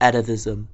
Ääntäminen
IPA : /ˈætəˌvɪzəm/ IPA : [ˈæɾəˌvɪzəm]